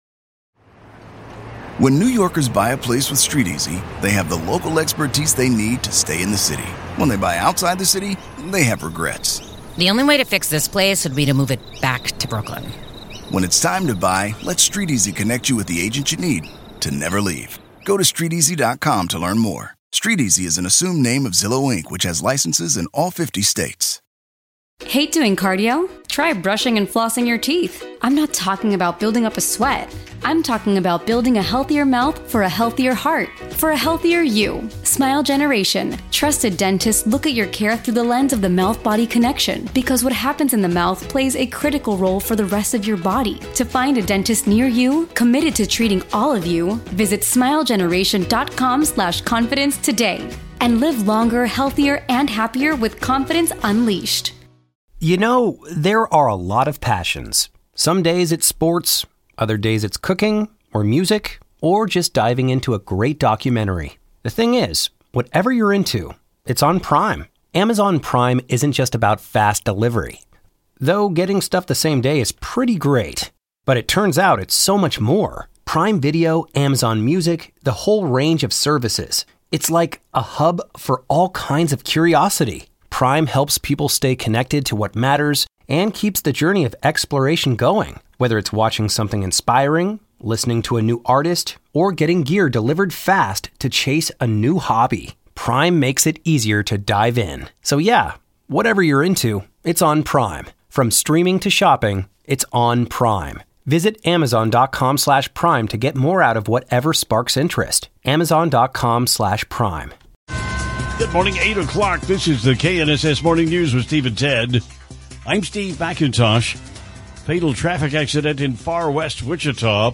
fully produced news and entertainment program aired live each weekday morning from 6a-9a on KNSS